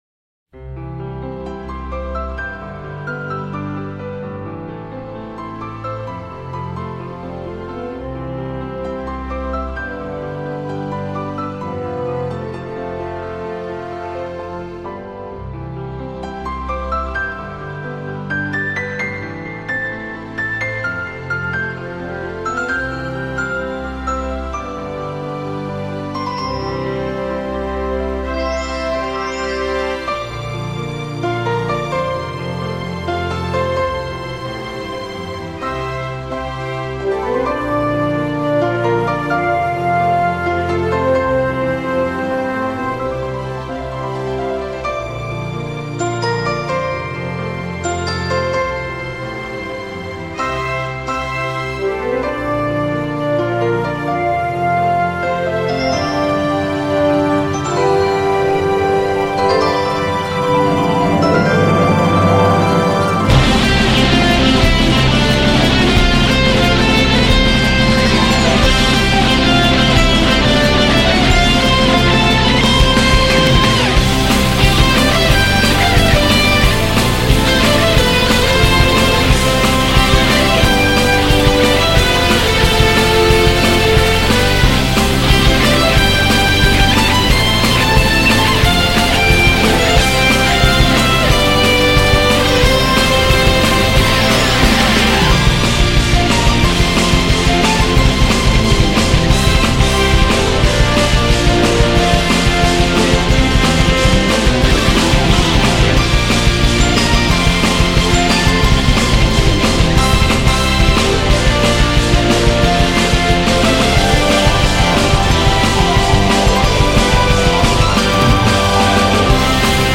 곡은 Orchestral Fusion Rock 인데
진짜 몽환적인 동화적인 곡입니다.